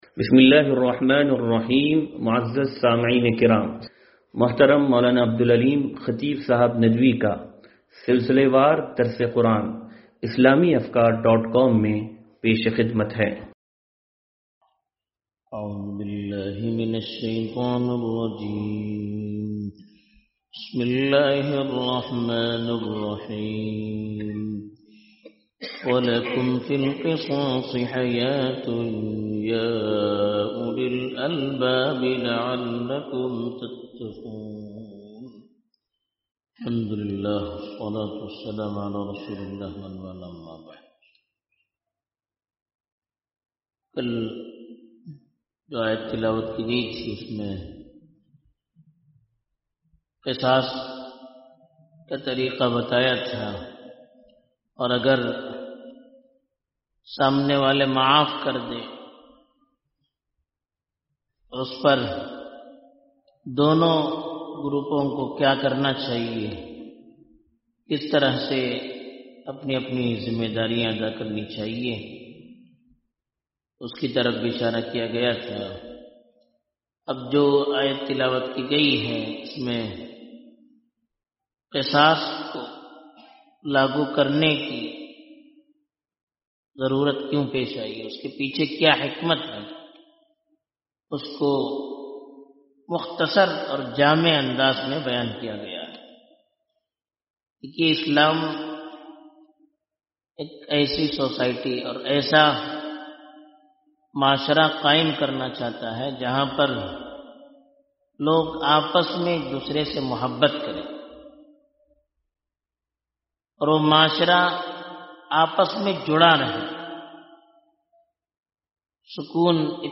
درس قرآن نمبر 0129